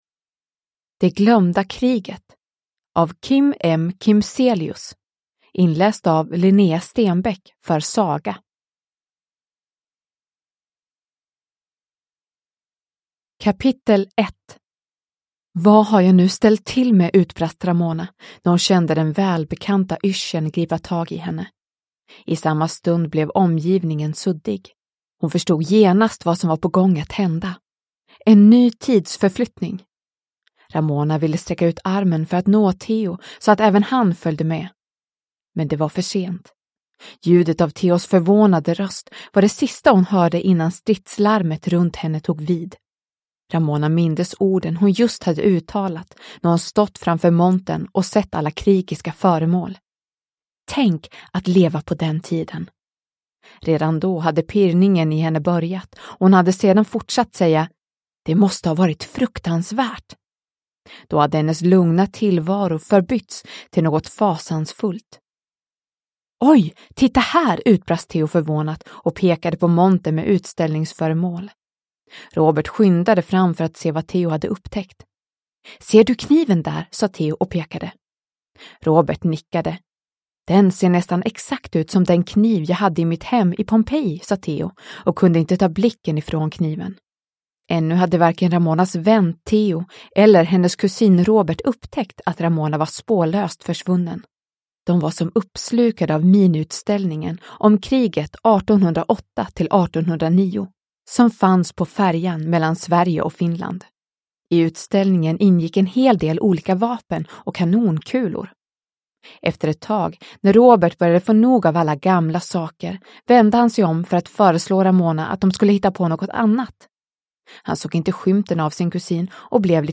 Det glömda kriget 1808–1809 – Ljudbok – Laddas ner